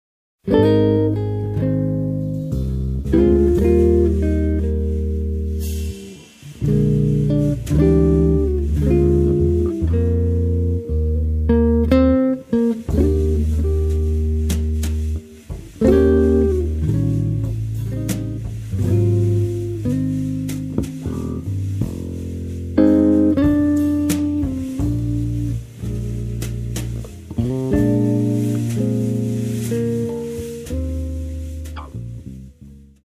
rheinisches Volkslied